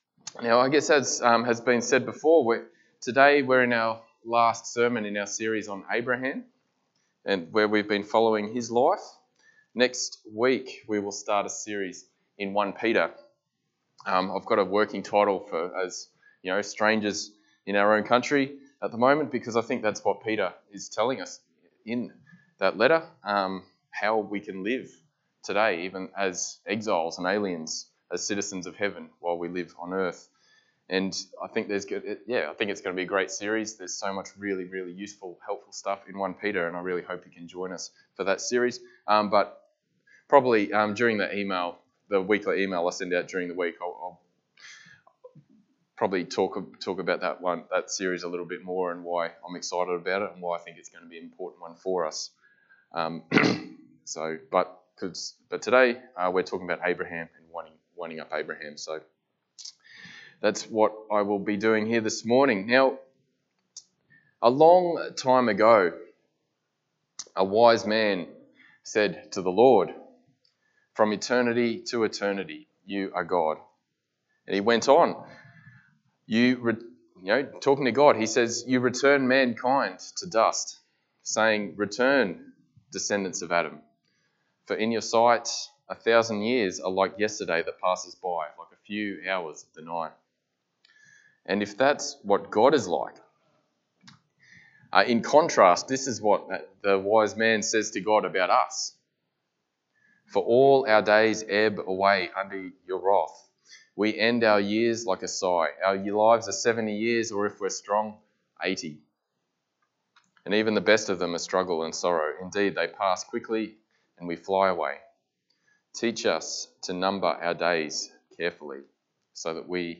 Passage: Genesis 25:1-11 Service Type: Sunday Morning